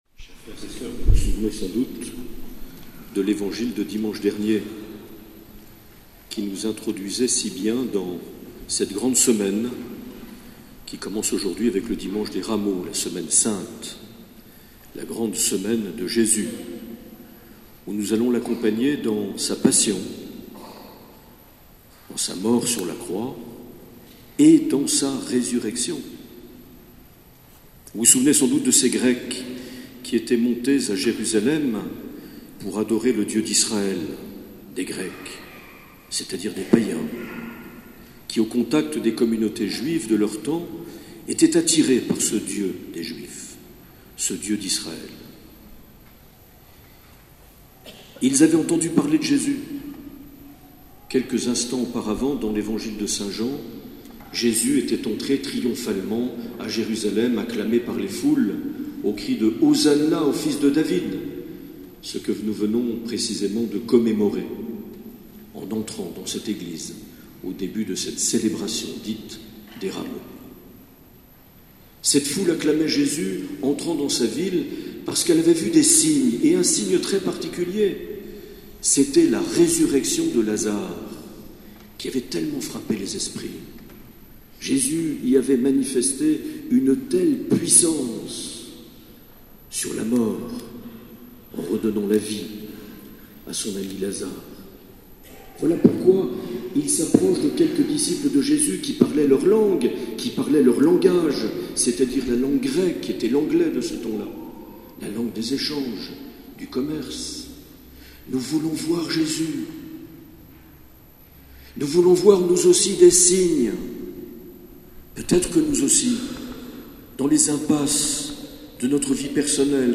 25 mars 2018 - Eglise sainte Eugénie Biarritz - Journée diocésaine des Jeunes
Une émission présentée par Monseigneur Marc Aillet